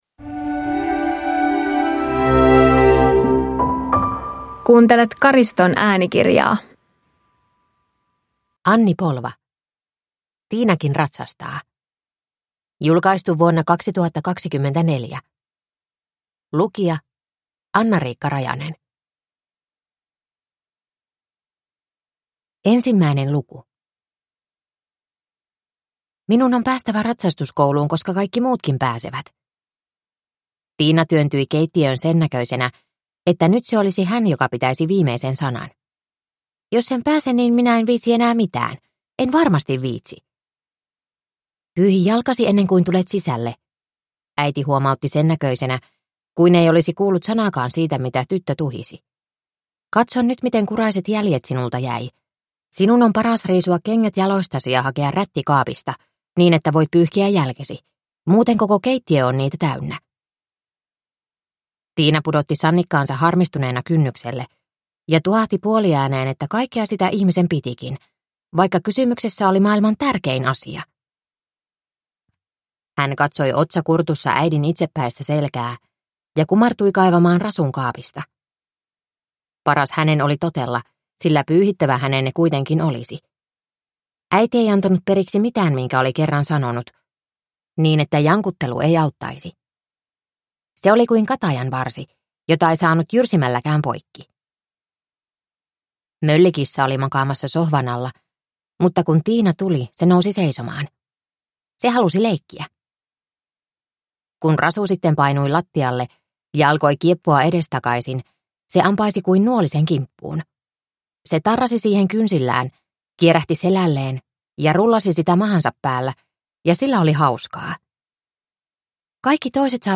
Tiinakin ratsastaa (ljudbok) av Anni Polva